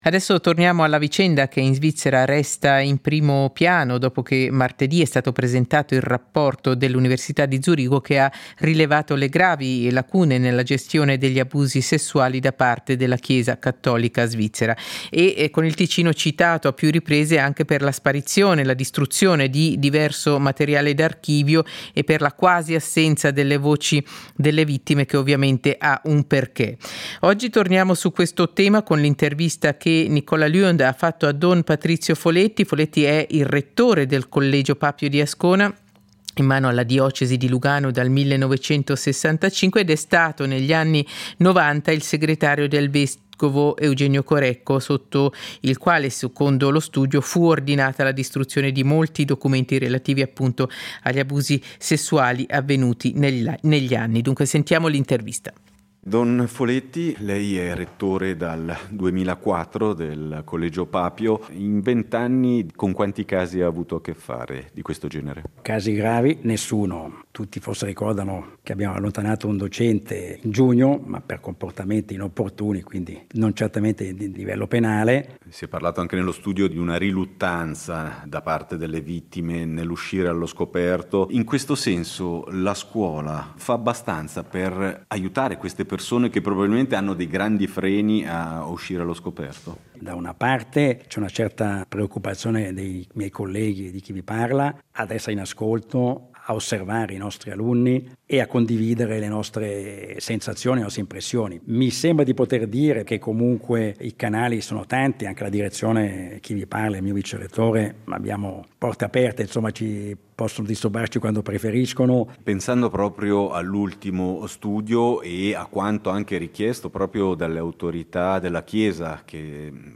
SEIDISERA del 15.09.2023: Abusi sessuali nella Chiesa: l'intervista